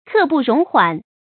注音：ㄎㄜˋ ㄅㄨˋ ㄖㄨㄙˊ ㄏㄨㄢˇ
讀音讀法：